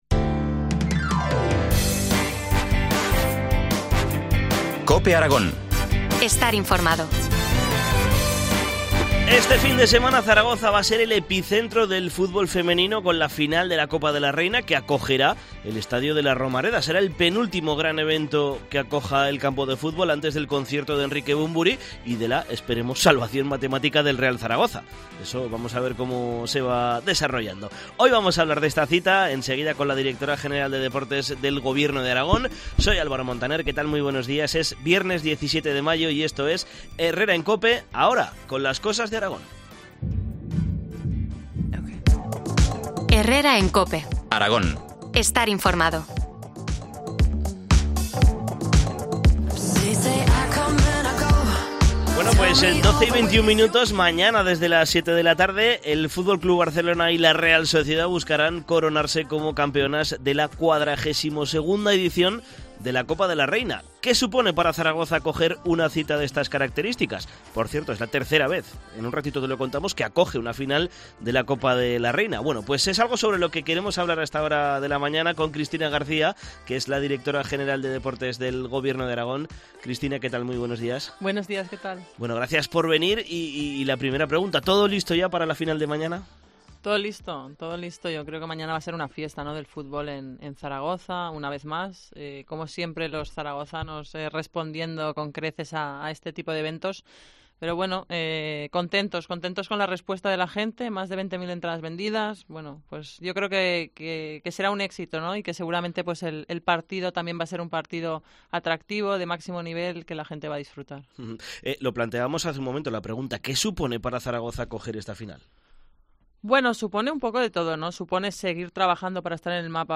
Entrevista a la Directora General de Deportes Cristina García, sobre la final de la Copa de la Reina